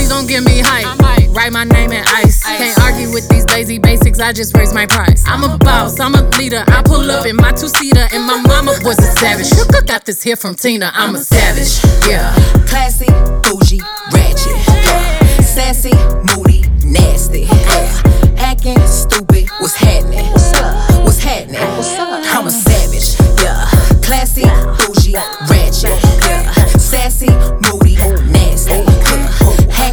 Genre: Hip-Hop/Rap